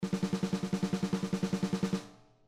Oddly, neither the default Studio Kit (supplied with Groove Agent) nor the Simon Phillips Jazz Drums render rolls in a realistic way.
Simon Phillips Jazz Drums: